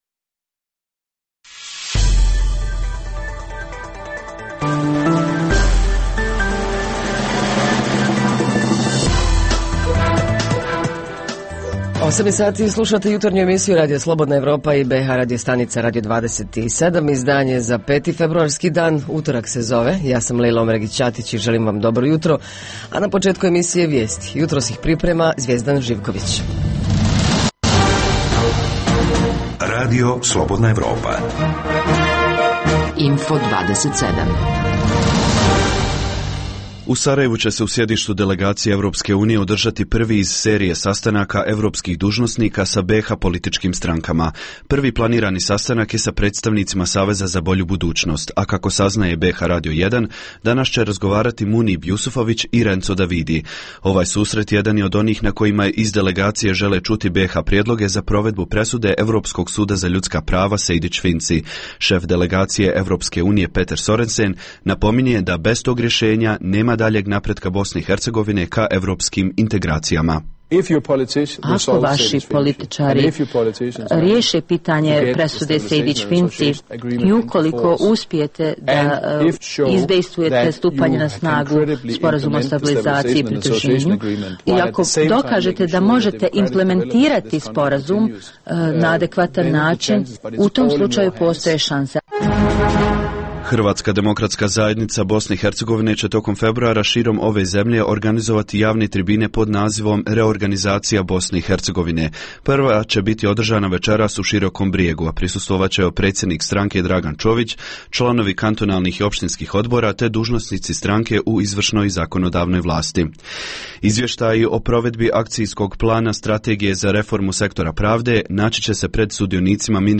O njima nam govore dopisnici iz Doboja, Prijedora i Jablanice.